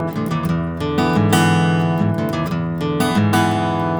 Index of /90_sSampleCDs/ILIO - Fretworks - Blues Guitar Samples/Partition G/120BARI RIFF